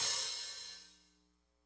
hihat03.wav